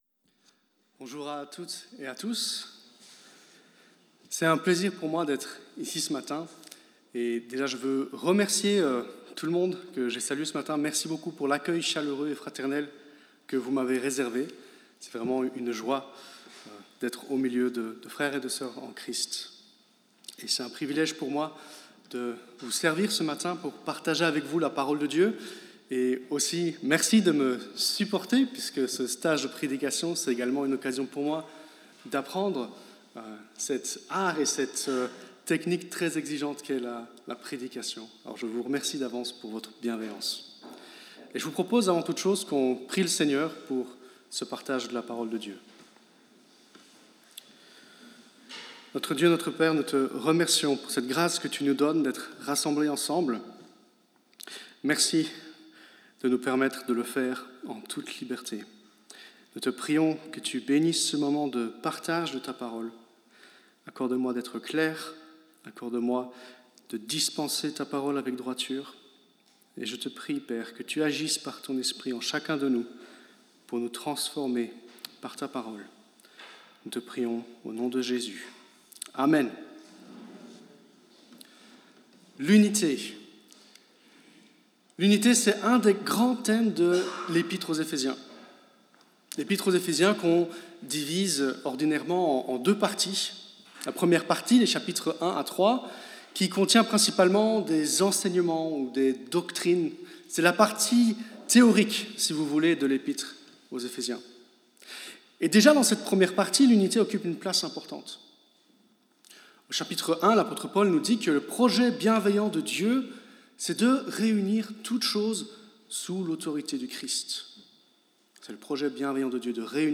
Ecouter le message